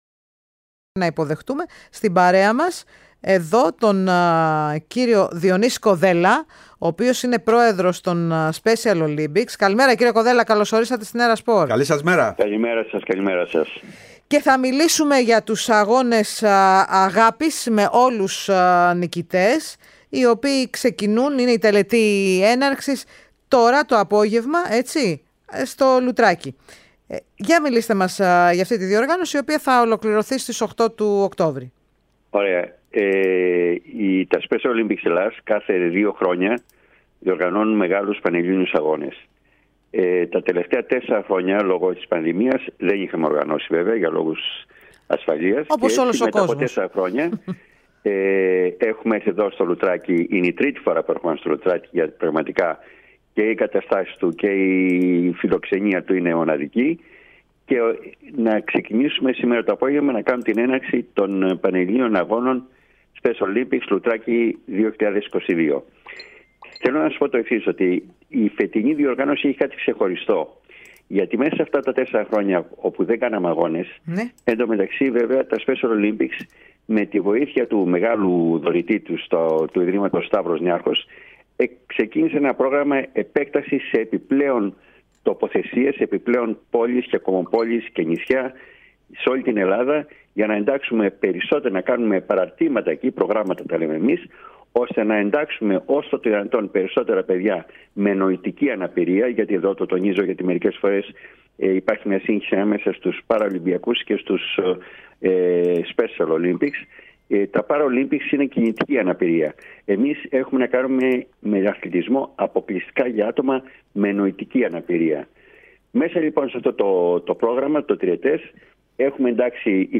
μίλησε στην ΕΡΑΣΠΟΡ και την εκπομπή “η Λαίδη και ο Αλήτης”